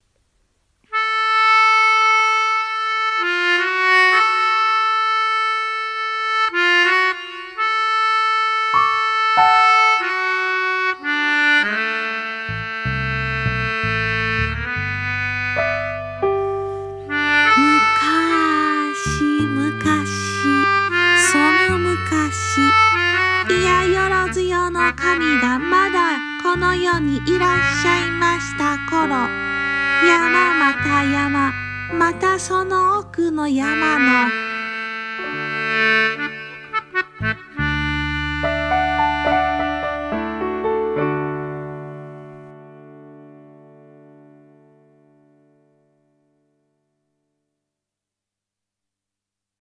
まんが日本昔ばなし [十二支の由来 編] ナレーション・市原悦子 出典・表記なし nanaRepeat